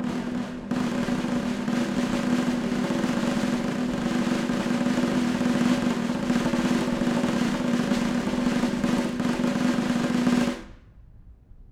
Snare2-rollSN_v1_rr1_Sum.wav